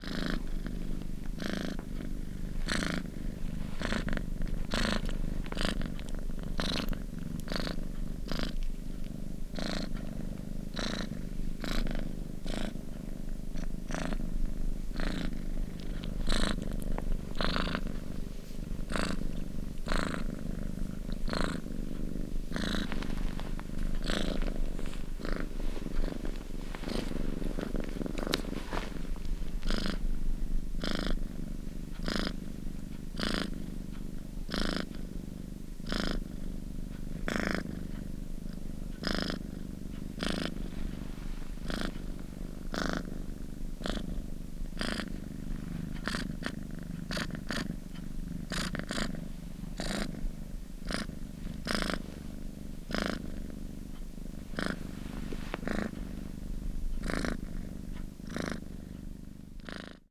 Cat purr
Category 🐾 Animals
animal animals cat Cat cats cute domestic feline sound effect free sound royalty free Animals